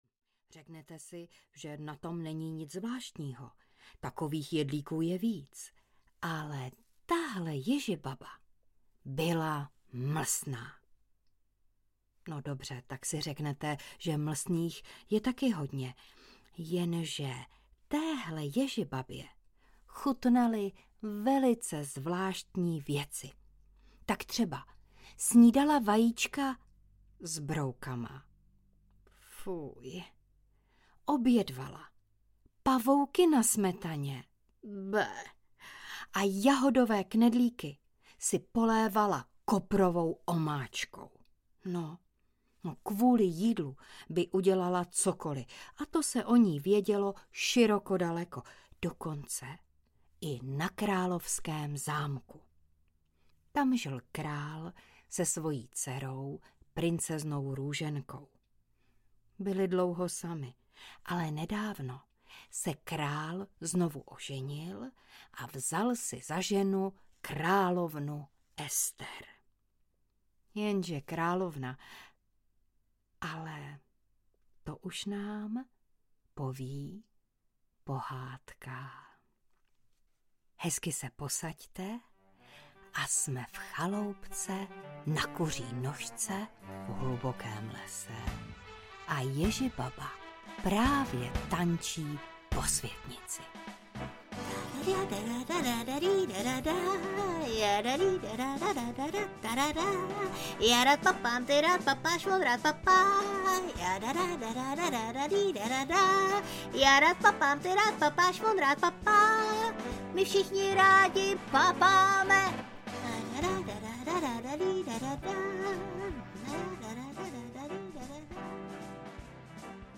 O mlsné ježibabě audiokniha
Ukázka z knihy